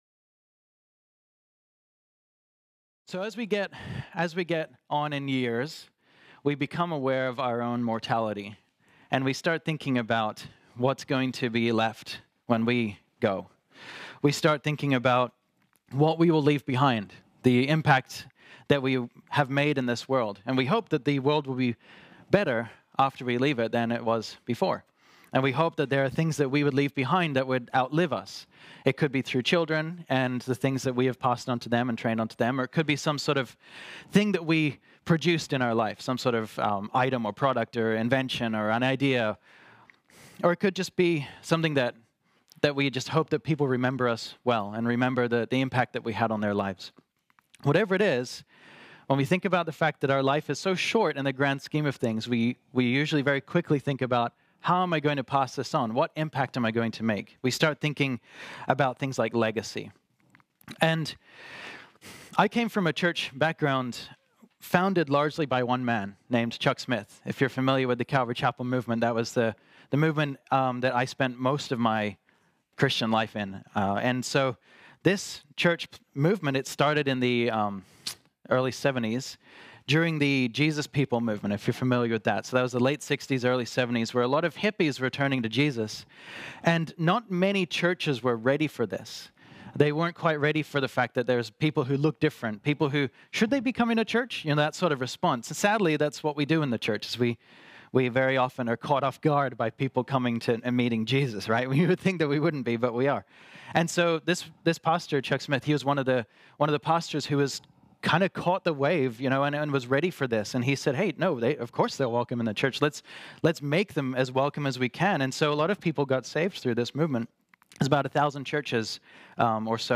This sermon was originally preached on Sunday, August 4, 2019.